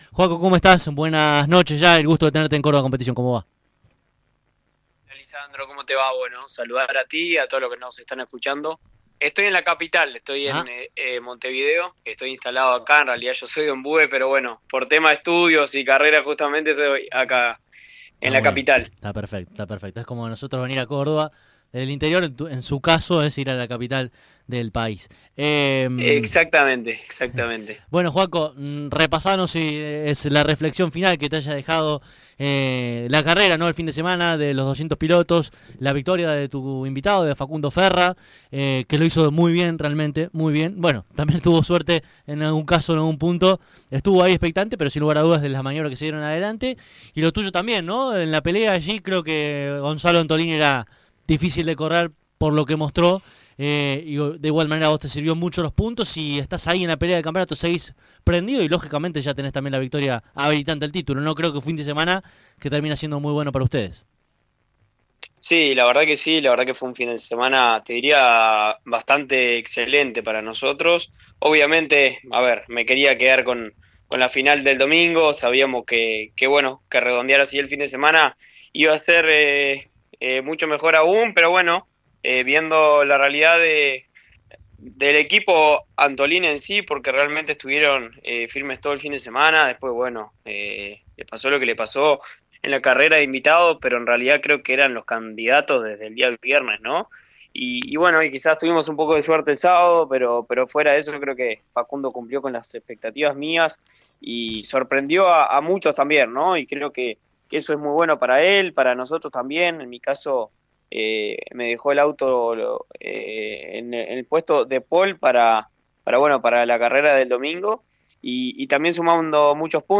Escuchá aquí debajo la palabra del piloto charrúa: